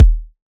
99Sounds x Monosounds - Kick - 009 - D#.wav